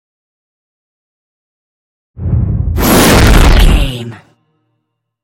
Scifi whoosh to hit 424
Sound Effects
dark
futuristic
intense
woosh to hit